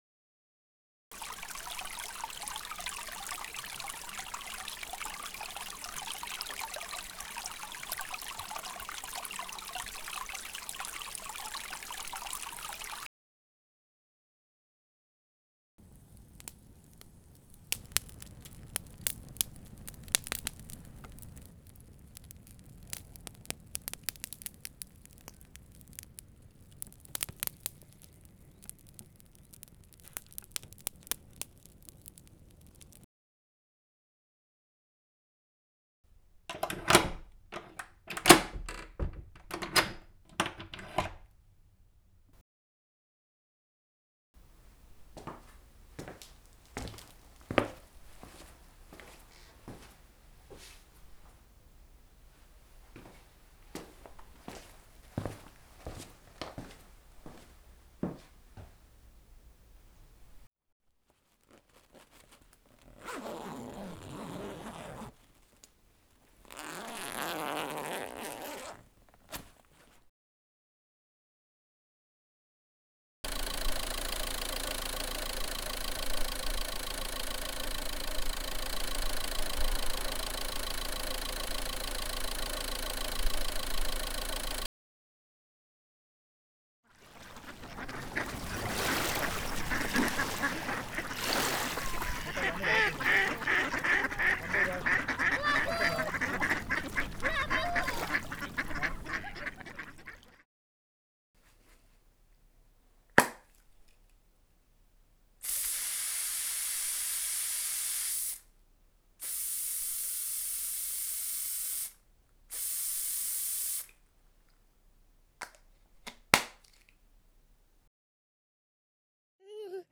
Cvičení - analýza zvuků
analýzazvuků-6.wav